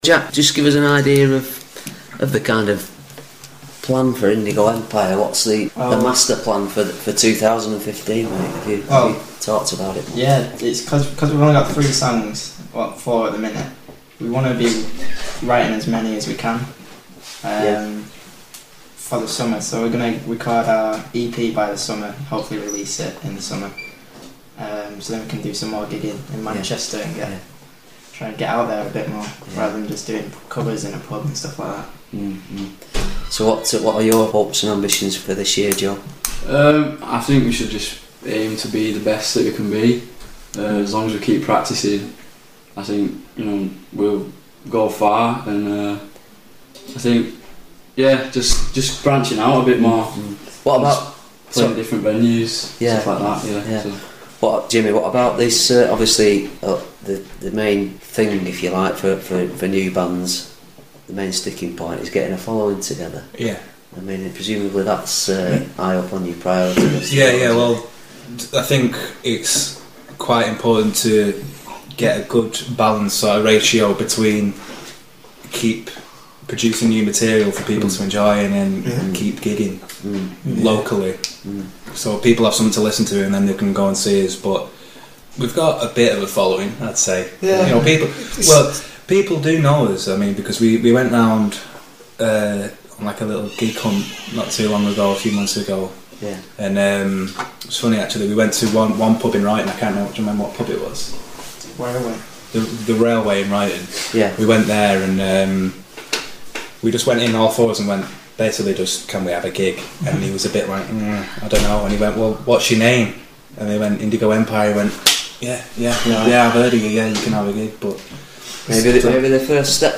exclusive interview with the teenage hopefuls as they discuss their future plans.